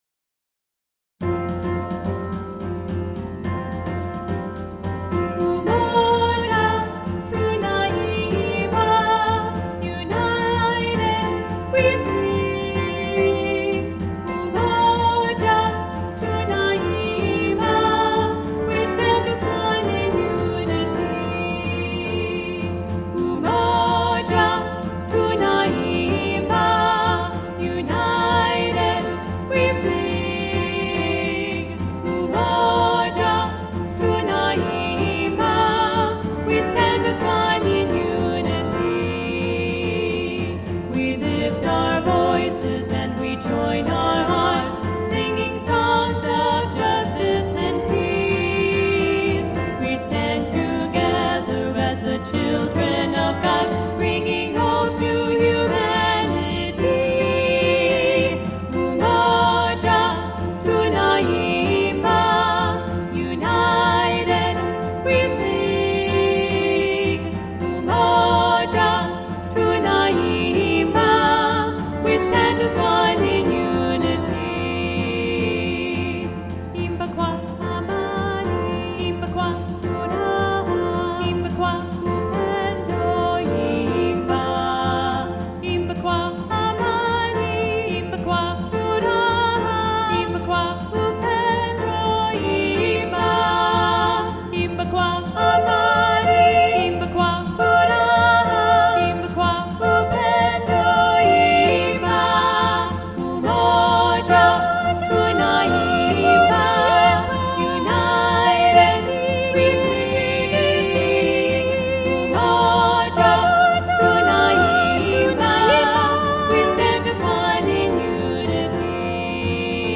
3-Part Mixed – Part 3b Muted